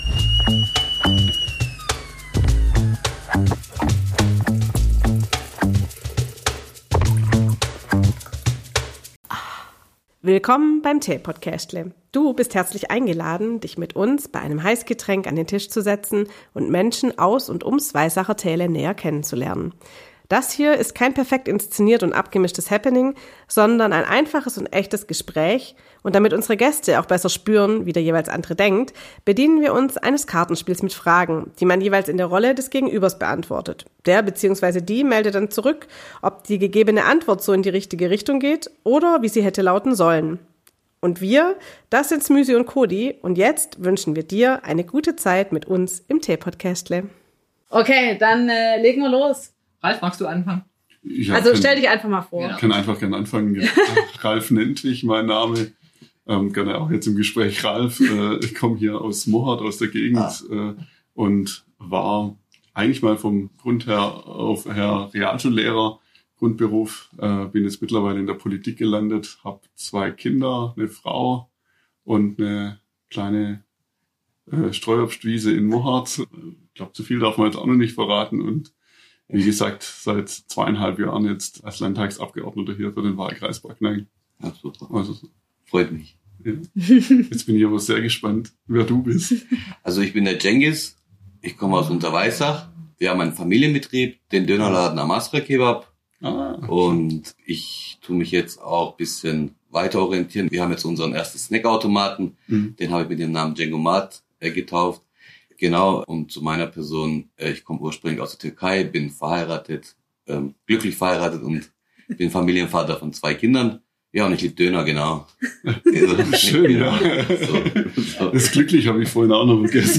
Freu Dich auf ein lustiges Gespräch quer durch alle Schubladen im Kopf mit kurzen DeepDives und praktischen Tipps für den Alltag zum Nachmachen ;) Schnapp Dir ein leckeres Getränk und dann viel Spaß beim Zuhören!